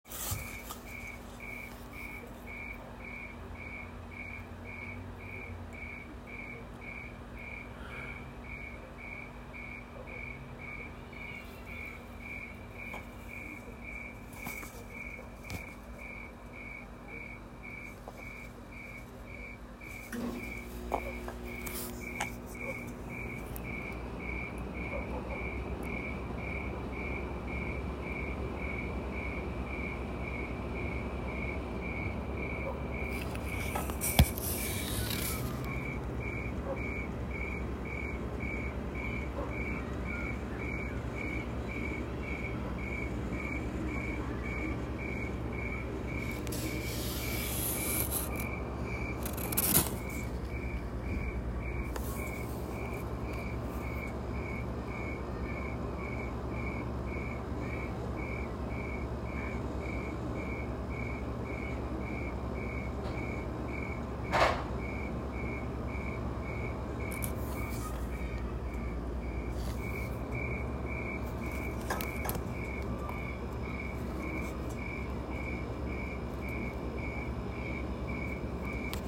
A Cricket Keeping Common Time
During late July, 2023 while visiting my mother in southeastern Washington, I slept on her couch with a window open to let in the cool evening air.  After a few moments, I heard the sound of a cricket, or similar insect.  God’s tiny percussionist was keeping perfect common (4/4) time at a rate of 100 beats per minute.
A metronome could not do better, and the tone of the beat held was certainly superior to the mechanical quality of  such a devise.I recorded its “beat” twice, the first for about 3.5 minutes duration, and the second for just over five minutes.